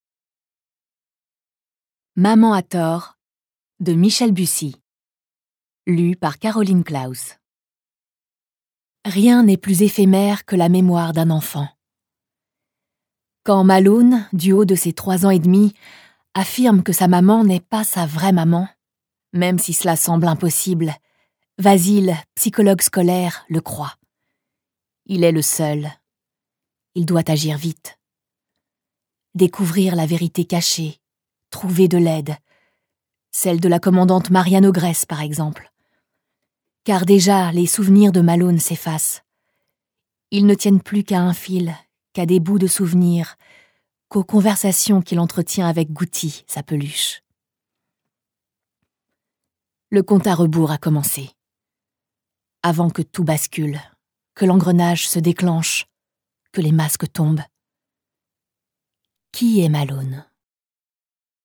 Extrait de mon 1er livre audio!
Livre audio